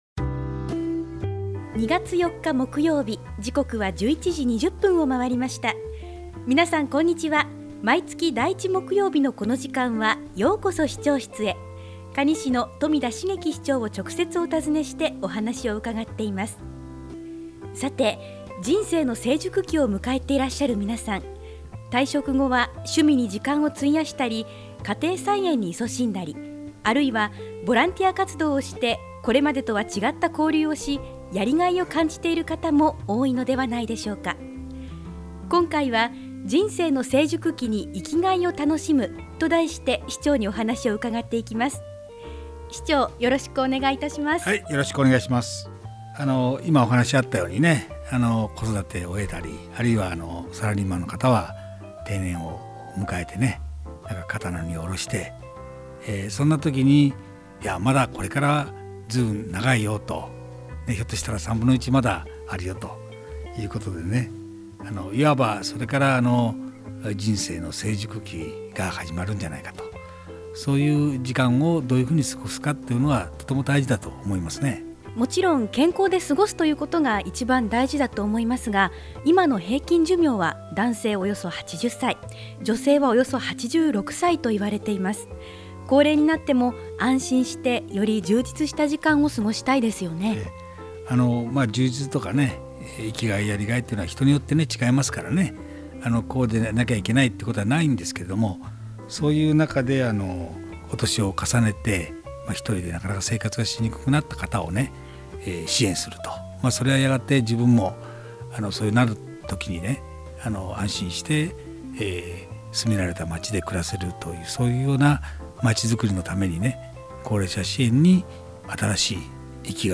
ようこそ市長室へ 2016-02-04 | ようこそ市長室へ 「ようこそ市長室へ」 可児市長室へ直接伺って、まちづくりの課題、魅力ある地域、市政情報などを 中心に、新鮮な情報を 冨田市長の生の声を、皆様にお届けする番組です。 ◎ 放送時間 毎月第１木曜日 １１：２０～ 可児市長 ： 冨田 成輝 ▼ 平成２８年 ２月 ４日 放送分 【今回のテーマ】 「人生の成熟期に生きがいを楽しむ」 Podcast: Download « ようこそ市長室へ ようこそ市長室へ »